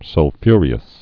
(sŭl-fyrē-əs)